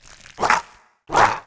attack_act_2.wav